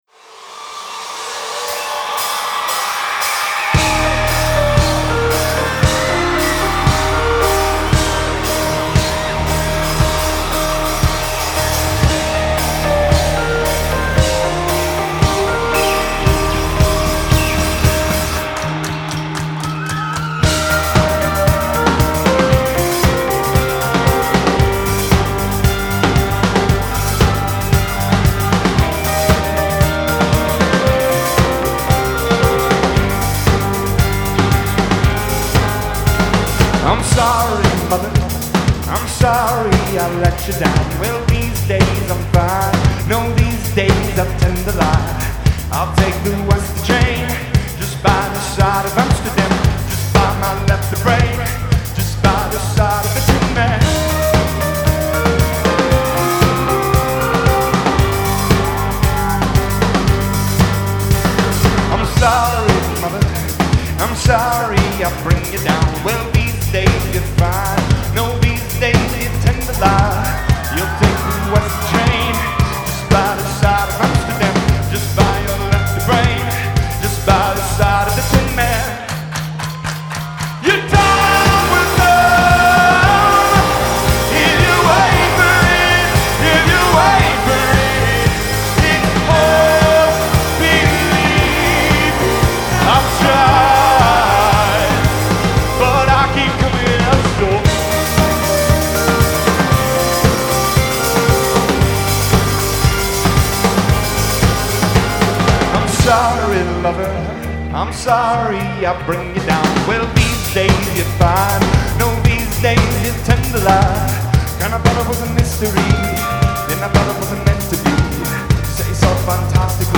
Genre : Alternative & Indie
Live From Red Rocks